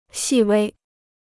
细微 (xì wēi): tiny; minute.